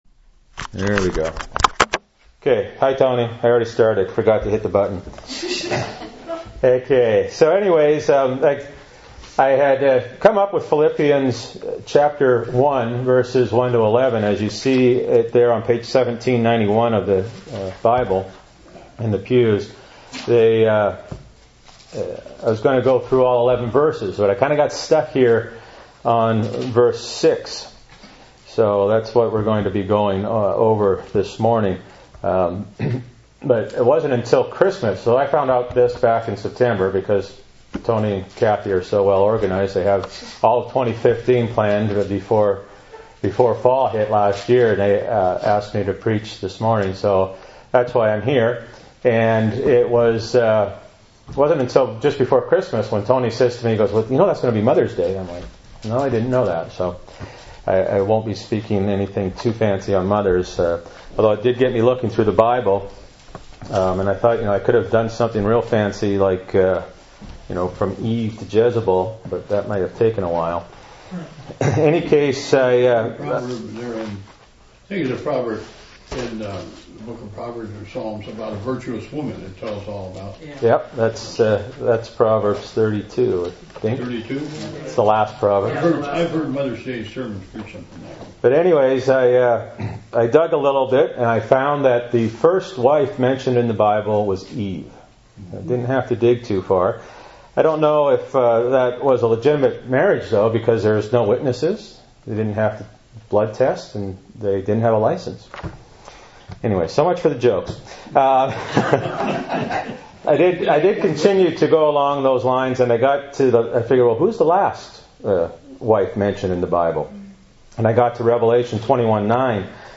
Bible Text: Philippians 1:6 | Preacher